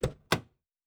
Car Door (3).wav